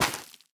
Minecraft Version Minecraft Version snapshot Latest Release | Latest Snapshot snapshot / assets / minecraft / sounds / block / netherwart / break2.ogg Compare With Compare With Latest Release | Latest Snapshot